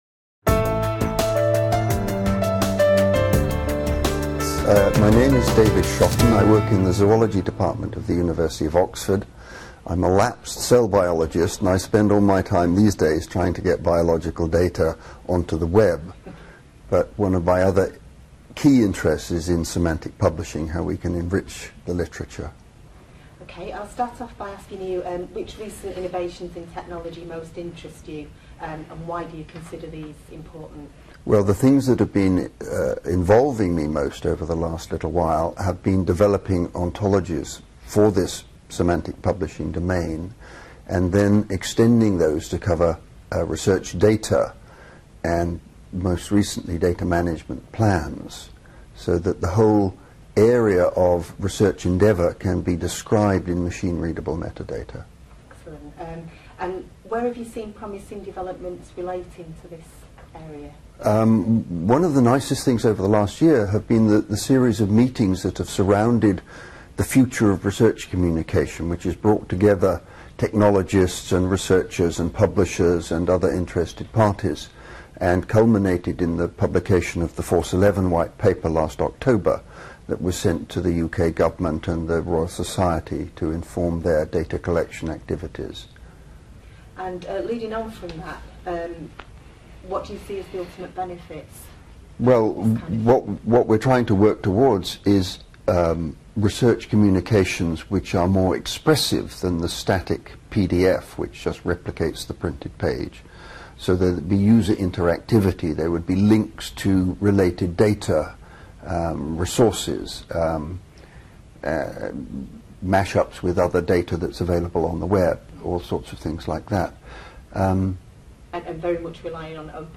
Interviews with Innovators